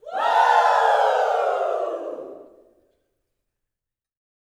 WOO  21.wav